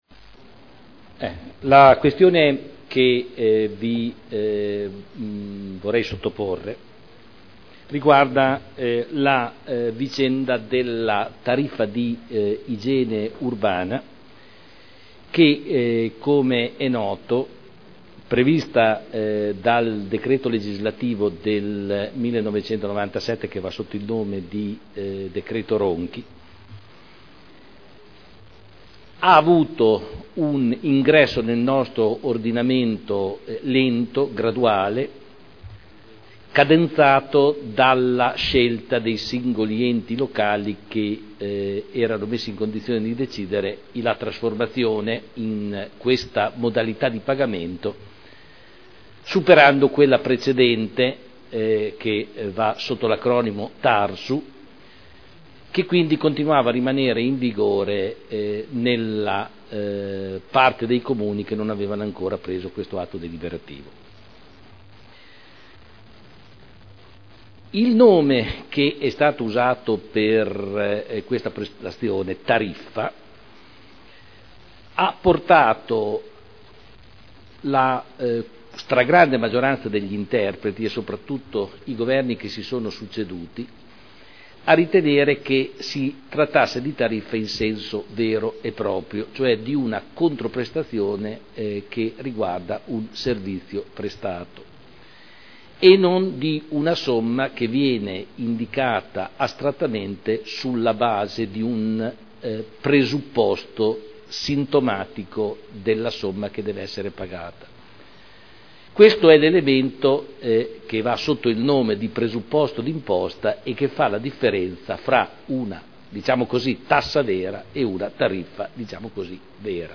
Giorgio Pighi — Sito Audio Consiglio Comunale
Comunicazioni del sindaco sulla T.I.A.